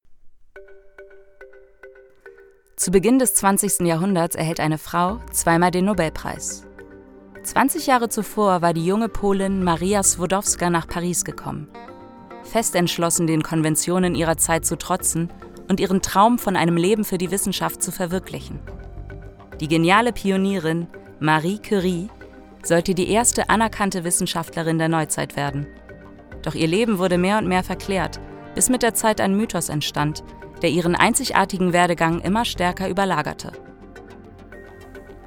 Documentaries
I specialize in bringing characters, commercials, and narrations to life with a warm, engaging, and versatile voice.
I work from my professional home studio, ensuring high-quality recordings, fast turnaround times, and a flexible, reliable service.
LowMezzo-Soprano